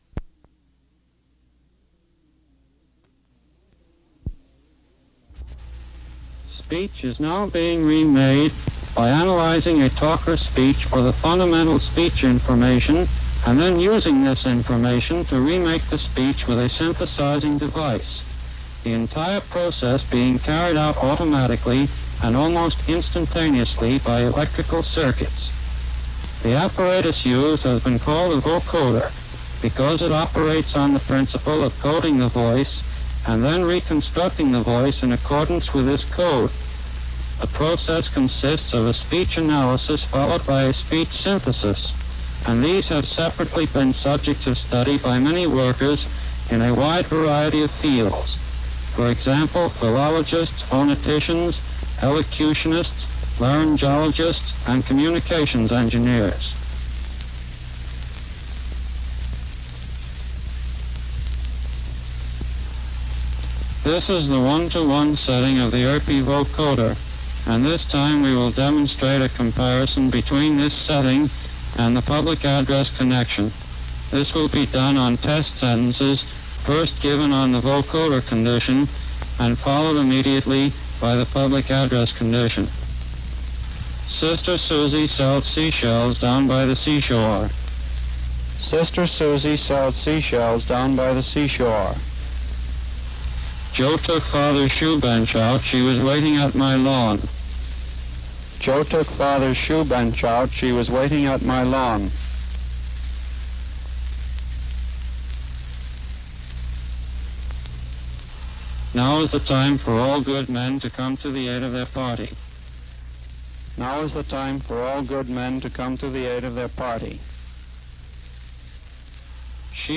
Vocoder
Ein unvoiced Signal erzeugt typischerweise einen eher „robotischen“ Klang, während ein voiced Signal durch die Ähnlichkeit zum Modulationssignal natürlicher wirkt.
Demonstration des ersten Vocoder 1939
vocoder.mp3